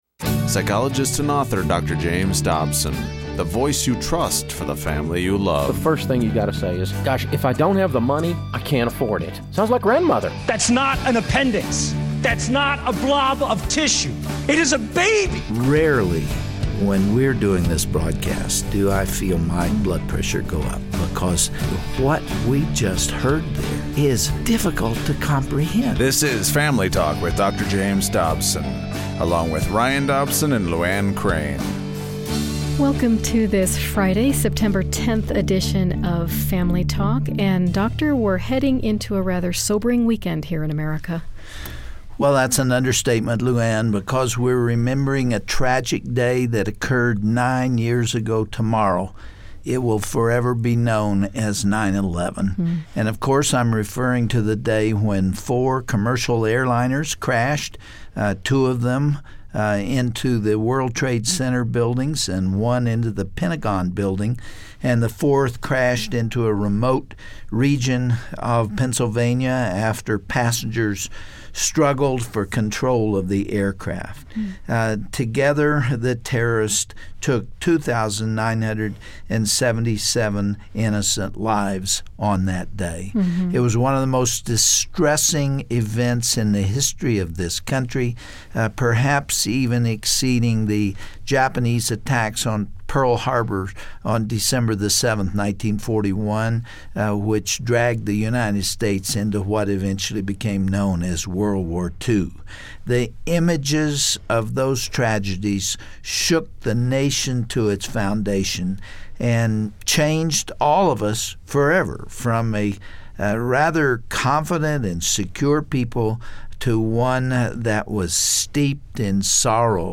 As we approach yet another anniversary of the horrific attack on America, the Family Talk team pauses to commemorate this sober time of year. On today's broadcast, hear Dr. Dobson's personal reflections on that tragic day, as well as his thoughts on current events being debated in our nation.